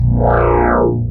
wah-formatted.wav